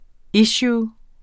Udtale [ ˈiɕuː ]